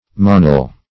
Monal \Mo*nal"\, n. (Zool.)